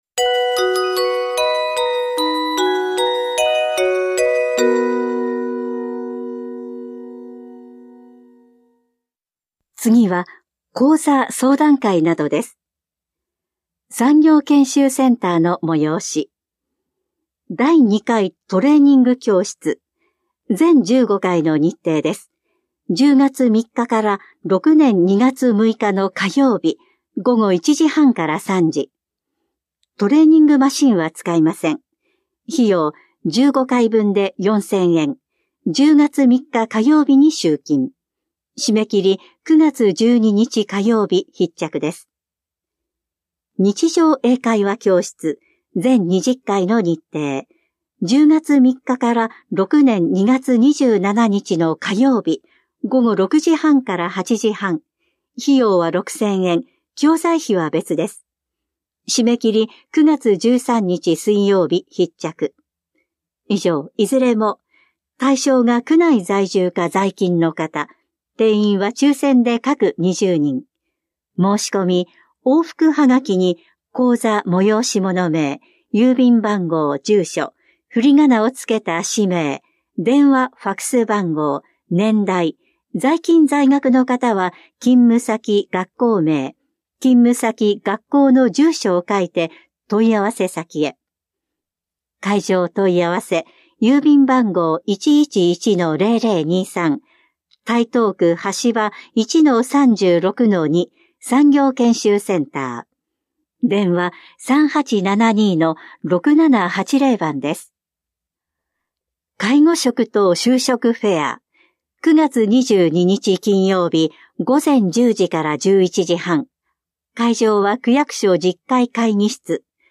広報「たいとう」令和5年8月20日号の音声読み上げデータです。